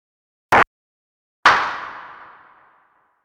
Elektron Gear Model:Cycles
Claps